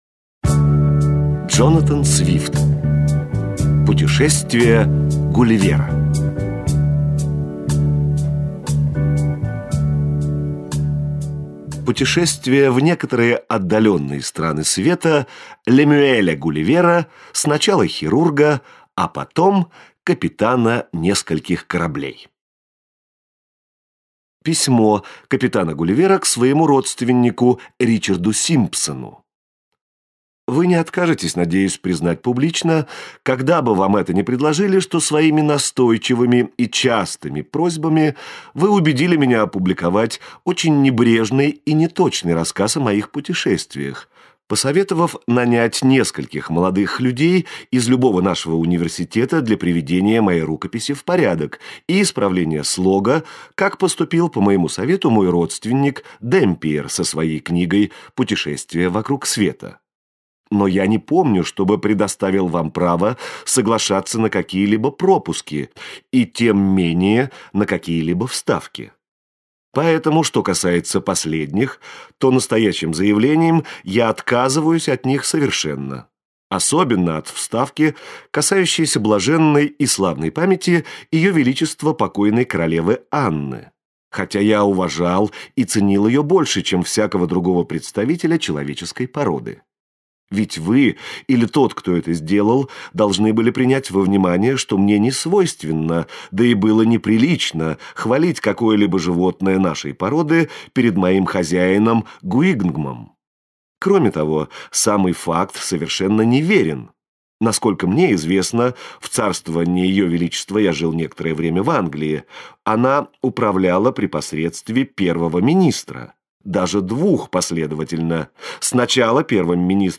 Аудиокнига Путешествия Гулливера - купить, скачать и слушать онлайн | КнигоПоиск